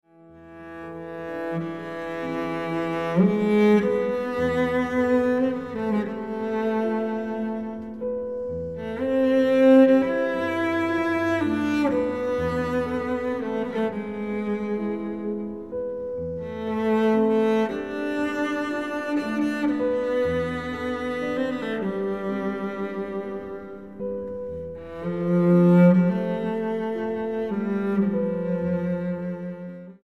violoncello
piano